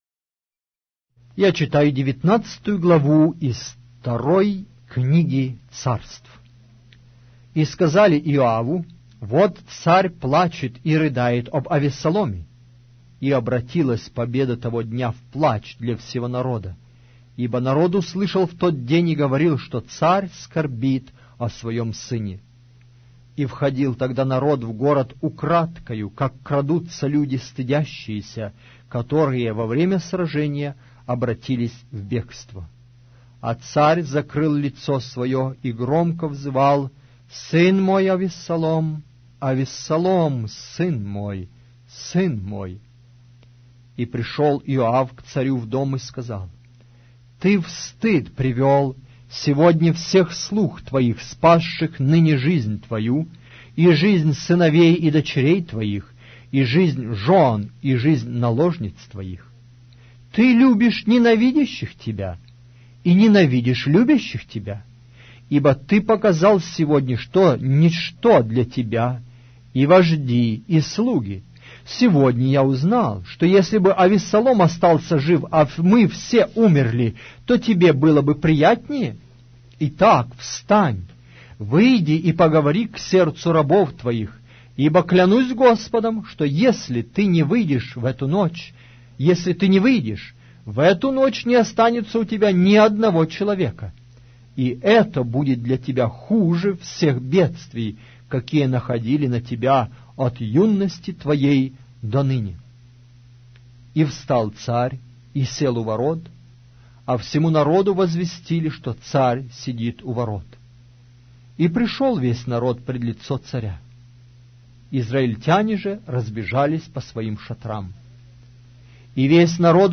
Аудиокнига: 2-я Книга Царств